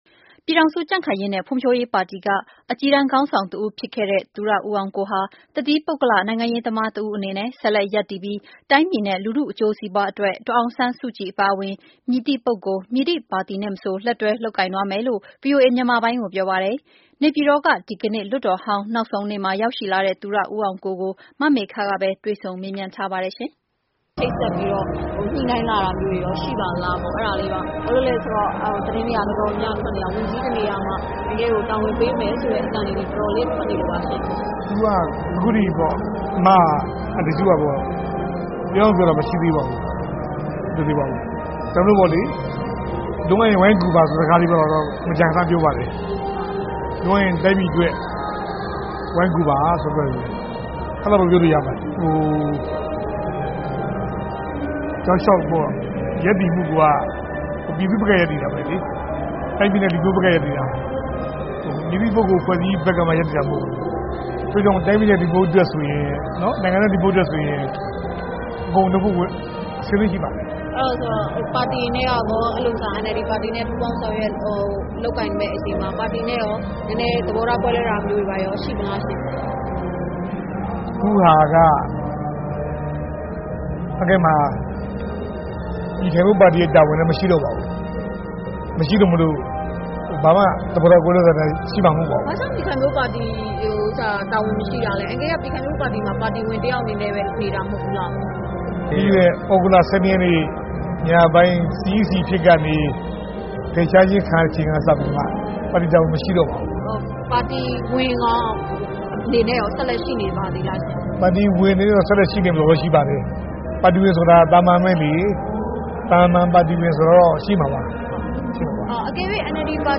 သူရဦးအောင်ကို နဲ့မေးမြန်း